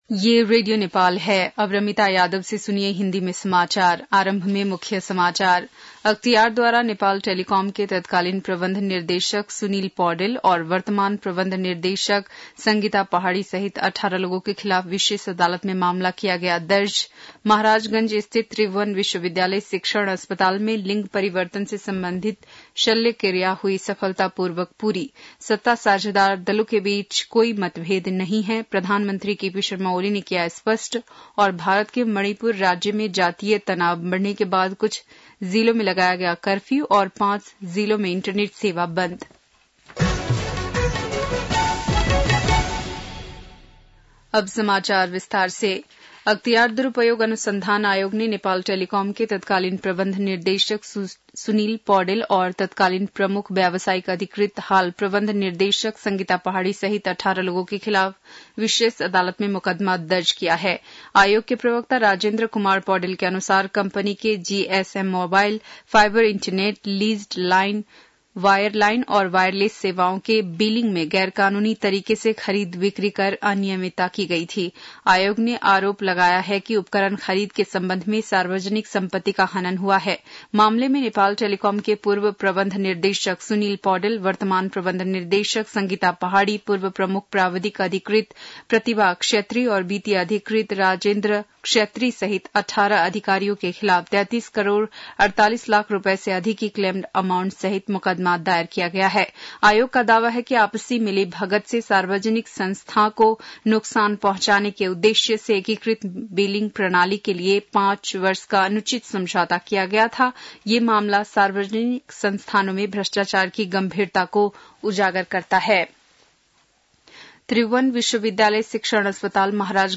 बेलुकी १० बजेको हिन्दी समाचार : २५ जेठ , २०८२